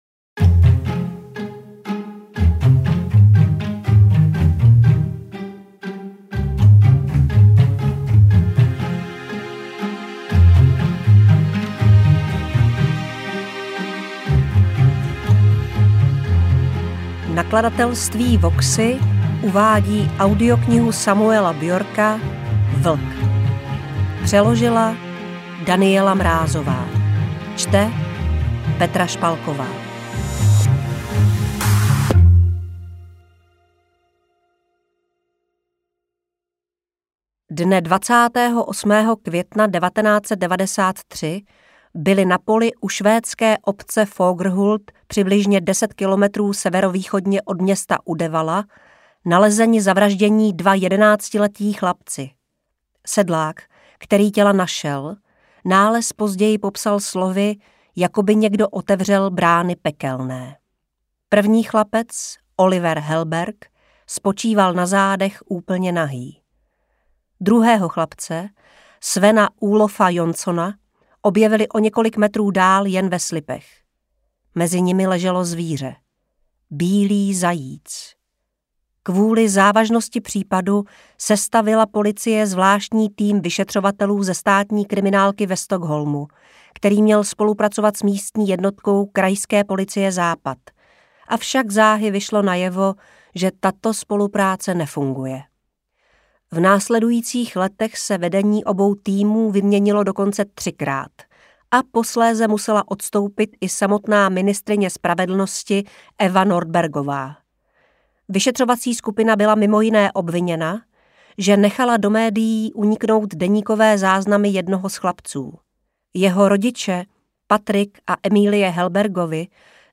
Interpret:  Petra Špalková
AudioKniha ke stažení, 86 x mp3, délka 14 hod. 33 min., velikost 792,8 MB, česky